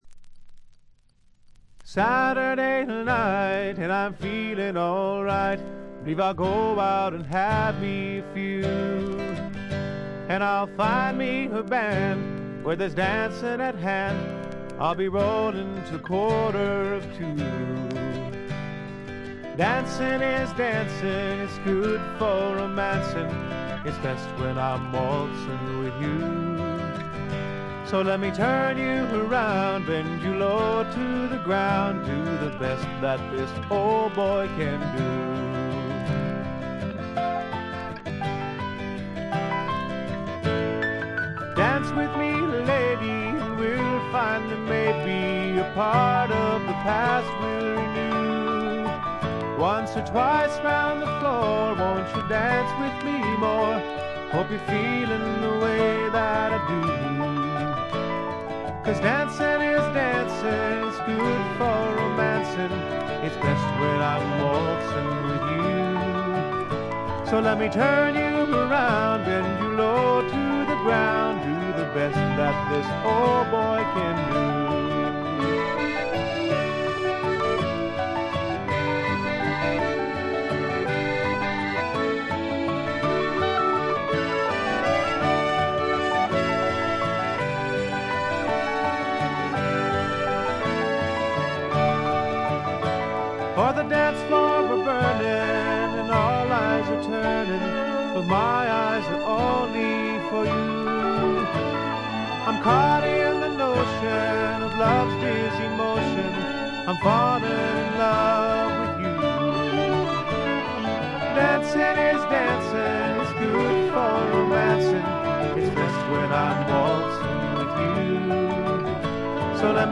チリプチ多め、散発的なプツ音も少々出ますが、普通に鑑賞できるものと思います。
知名度はいまいちながら実力派のいぶし銀のカントリーロックを聴かせます。
試聴曲は現品からの取り込み音源です。